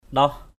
/ɗɔh/